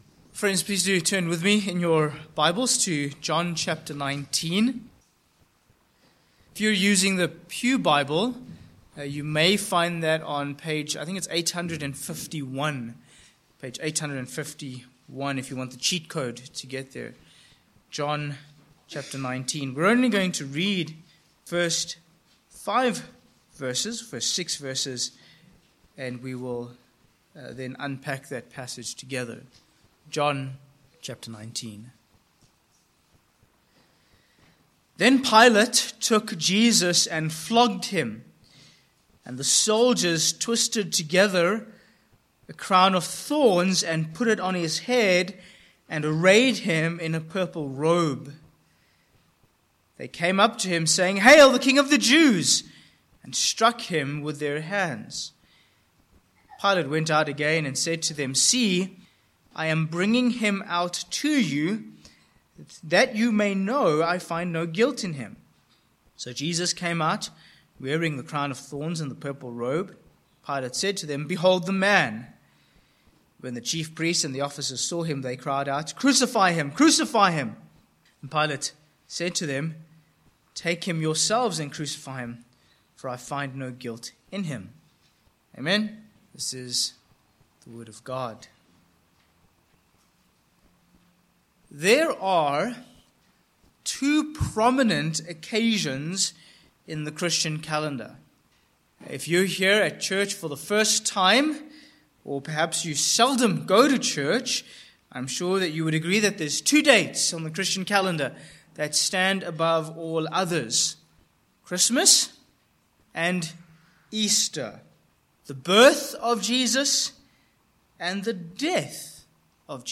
Sermon Points: 1. The Portrait of Jesus